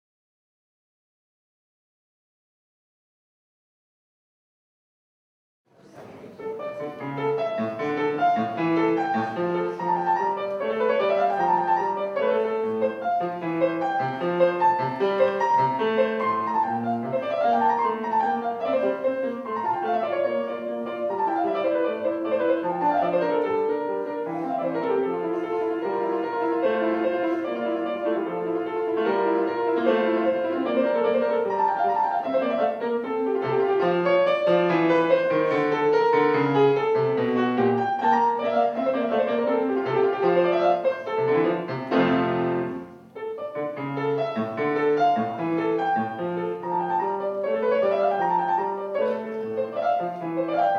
Andrea Bacchetti, pianista - Domenica 19 ottobre 2008 - B. Galuppi Sonata in Si bem. Magg